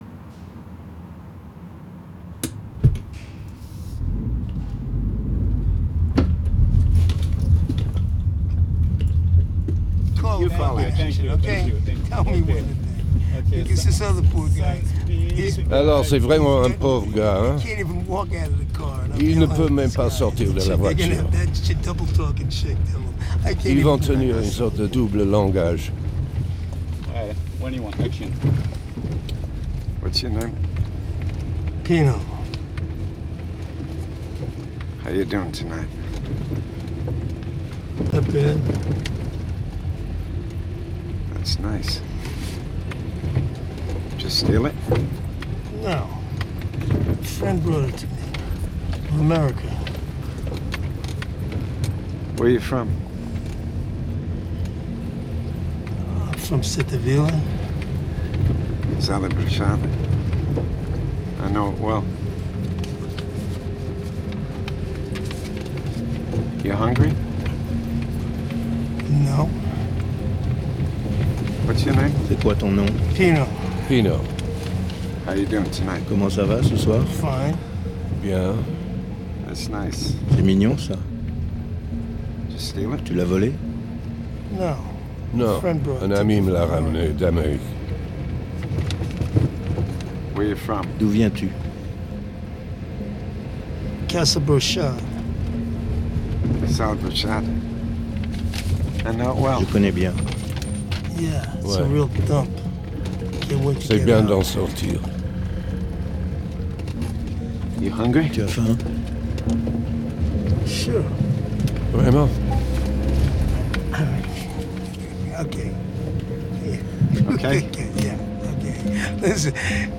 Pasolini, portrait d'artiste avec Abel Ferrara et Patti Smith, en son 3D
Une création sonore sur le tournage du film "Pasolini" avec Abel Ferrara, Patti Smith, Willem Dafoe et Isabelle Huppert